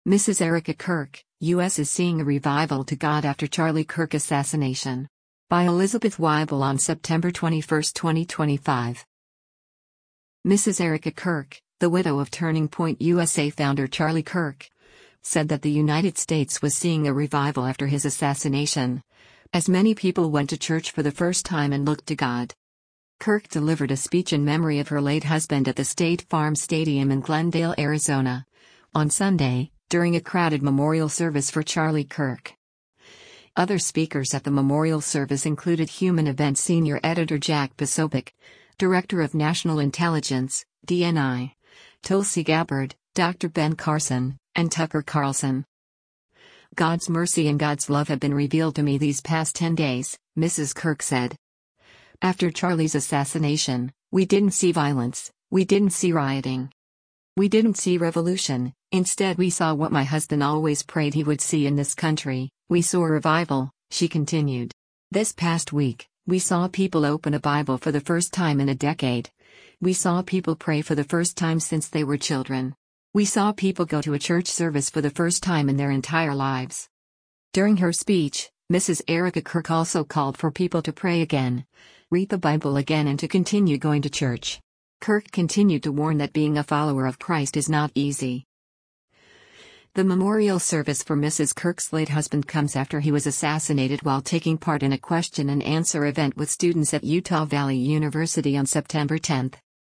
GLENDALE, ARIZONA - SEPTEMBER 21: Erika Kirk speaks during the memorial service for her hu
Kirk delivered a speech in memory of her late husband at the State Farm Stadium in Glendale, Arizona, on Sunday, during a crowded memorial service for Charlie Kirk.